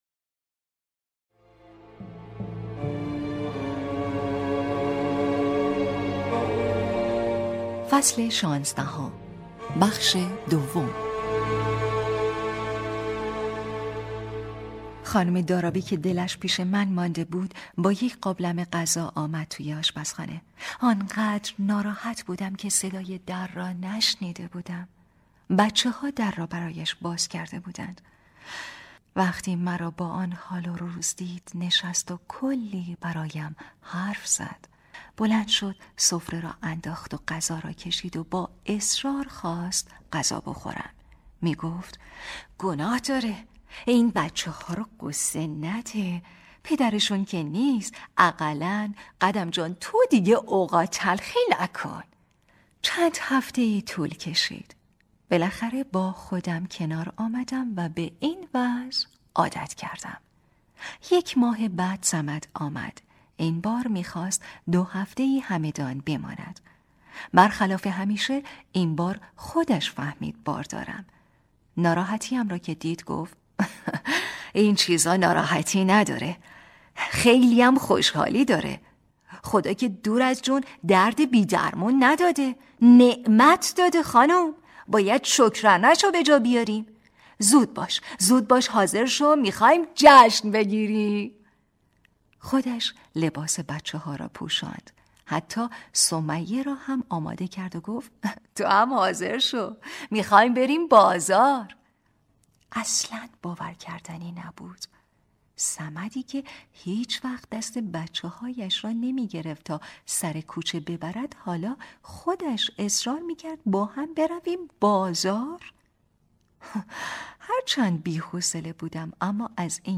کتاب صوتی | دختر شینا (16)